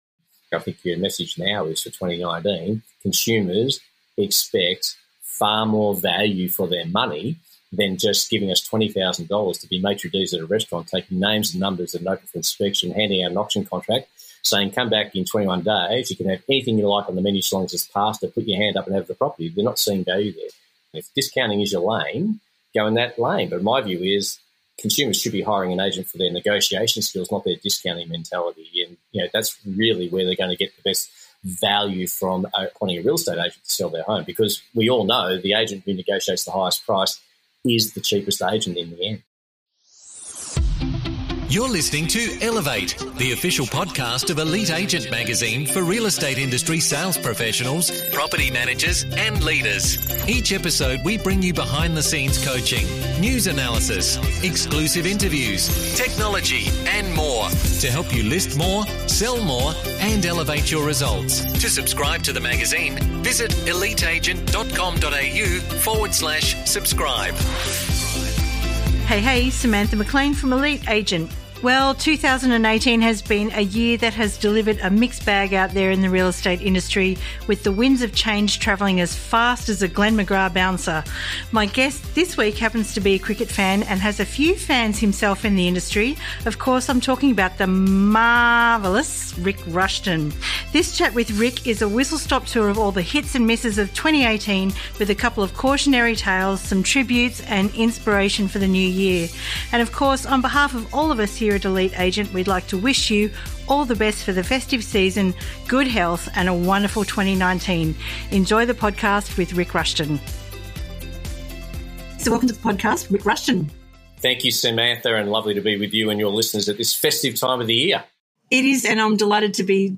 (There may also be an impersonation or two in there but we can’t say who!)